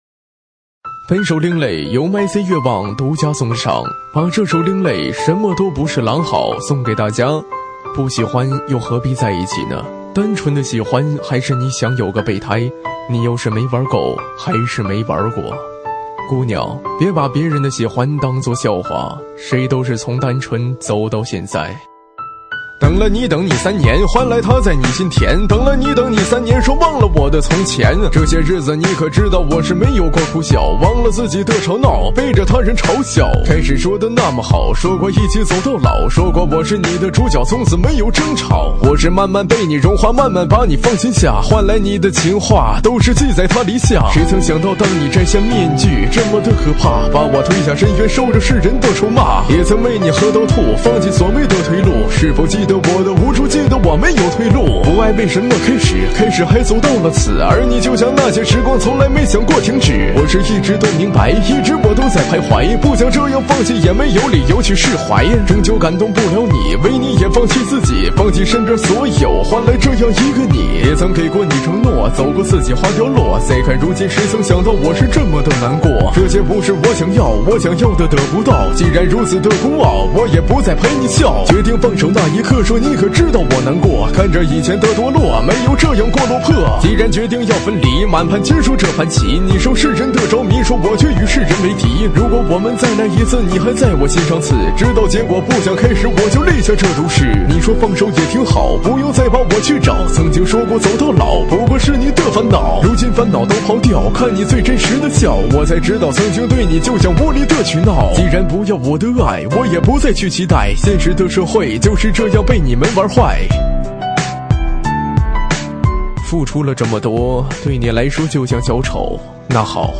伤感另类